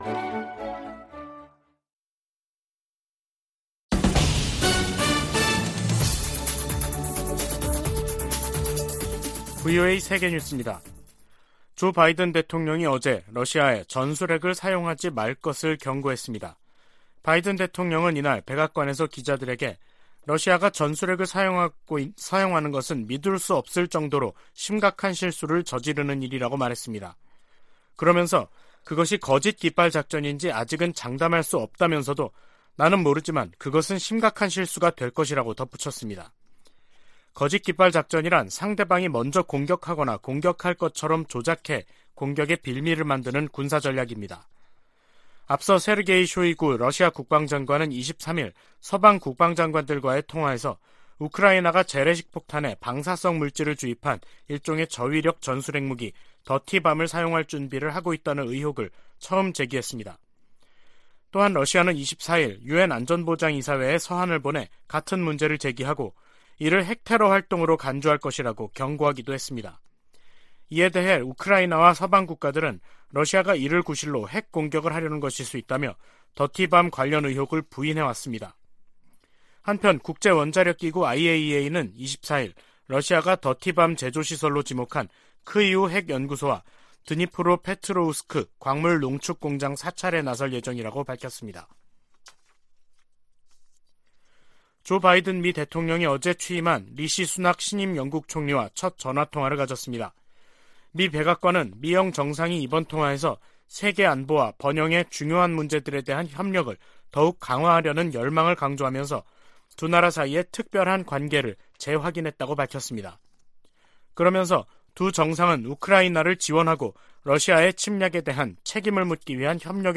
VOA 한국어 간판 뉴스 프로그램 '뉴스 투데이', 2022년 10월 26일 3부 방송입니다. 미국·한국·일본의 외교차관이 26일 도쿄에서 협의회를 열고 핵실험 등 북한의 추가 도발 중단을 강력히 촉구했습니다. 미 국무부는 북한이 7차 핵실험을 강행할 경우 대가를 치를 것이라고 경고한 사실을 다시 강조했습니다. 미 국방부는 한반도 전술핵 재배치와 관련한 질문에 강력한 억지력 보장을 위해 한국·일본 등 동맹과 긴밀히 협력할 것이라고 밝혔습니다.